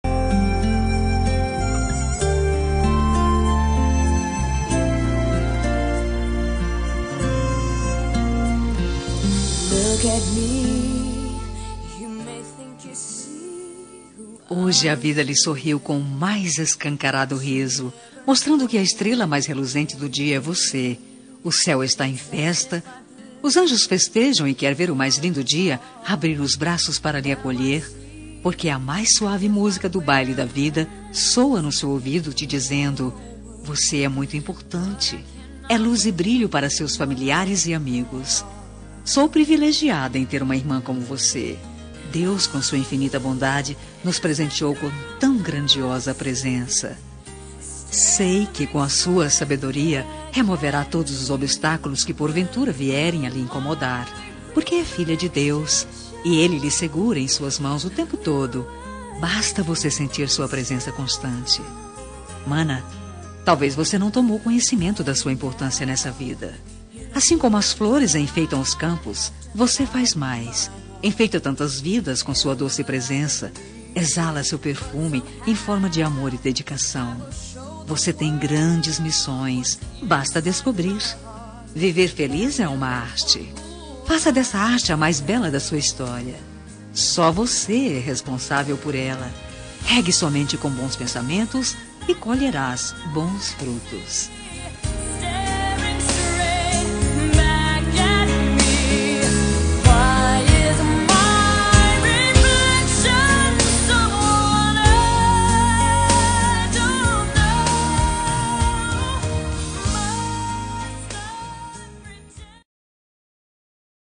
Homenagem ao Irmã – Voz Feminina – Cód: 313